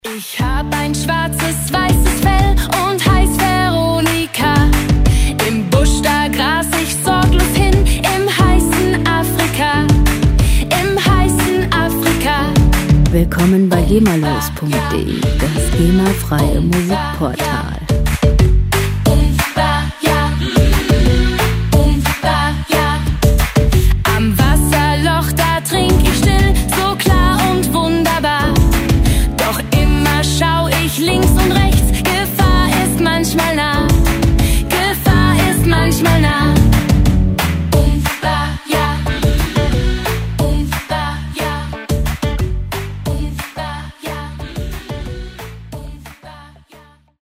Gema-freie Kinderlieder
Musikstil: Afro-Pop
Tempo: 90 bpm
Tonart: D-Dur
Charakter: interessant, anregend